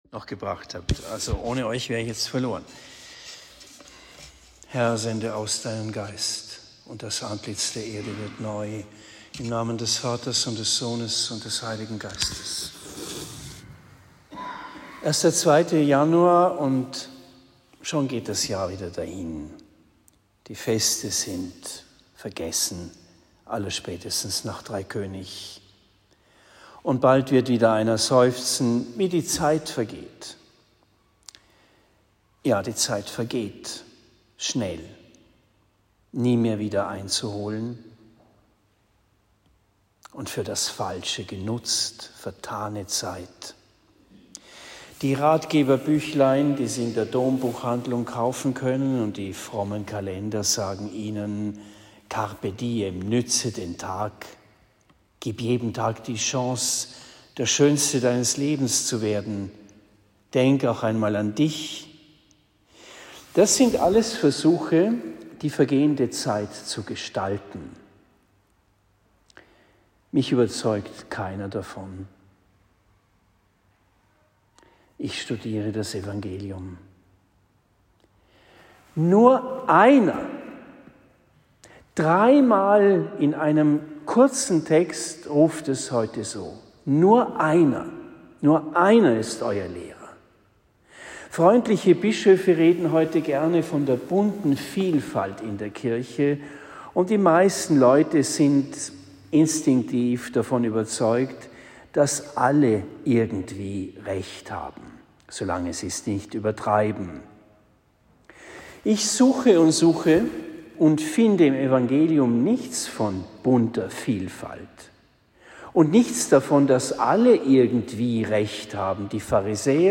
Predigt in Bischbrunn im Spessart